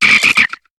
Cri de Pifeuil dans Pokémon HOME.